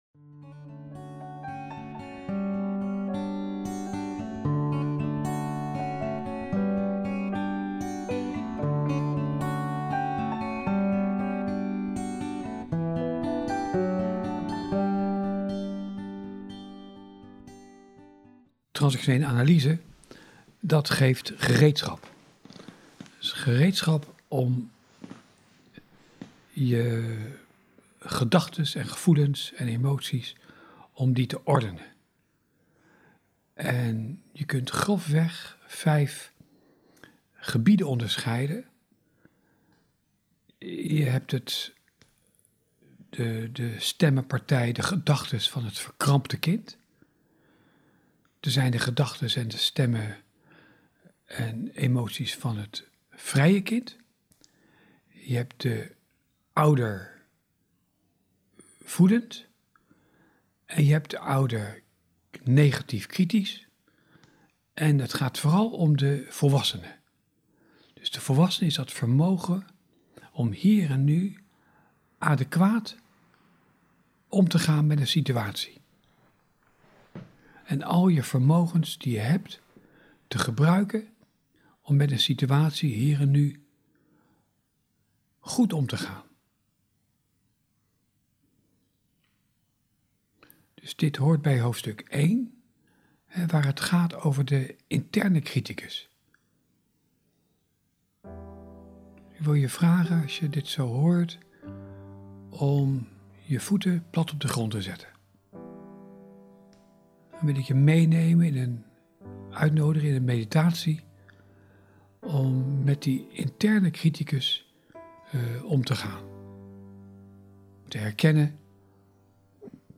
We onderzoeken hoe die innerlijke stemmen je verhinderen om de werkelijkheid in het hier en nu goed waar te nemen. In deze audiomeditatie kun je onderzoeken hoe dit werkt voor jou. https